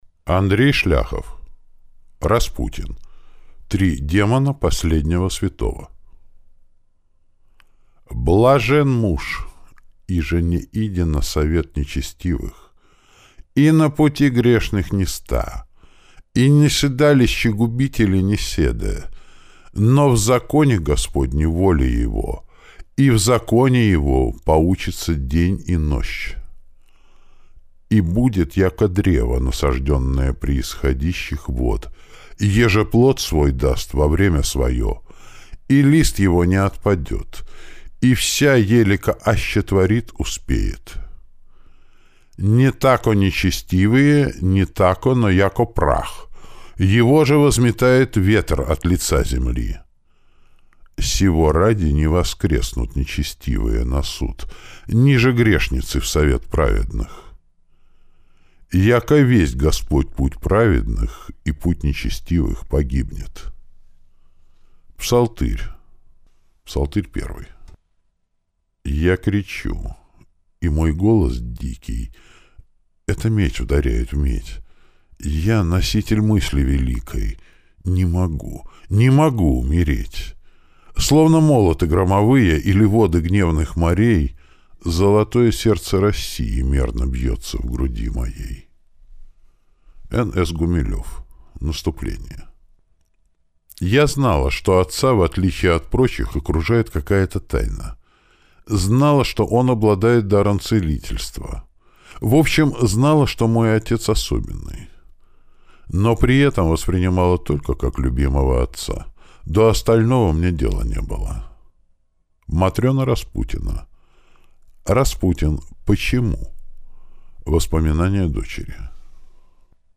Аудиокнига Распутин. Три демона последнего святого | Библиотека аудиокниг